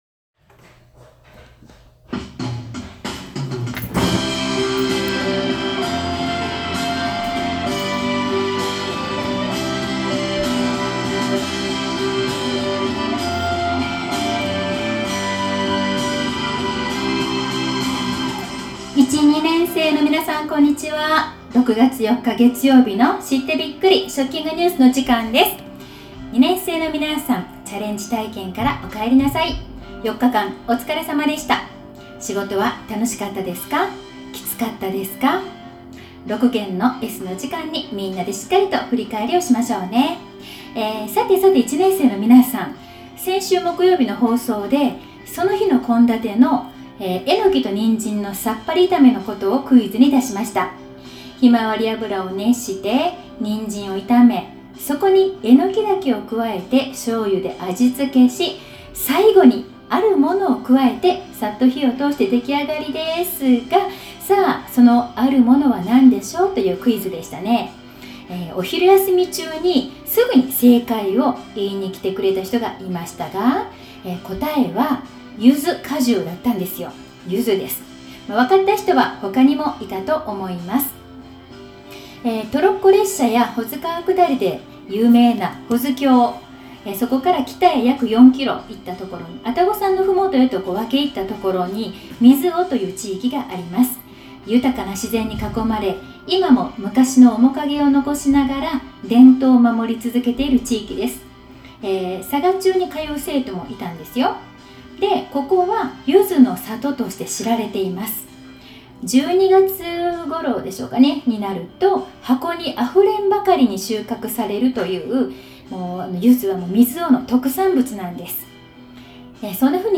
今日の給食＆食育放送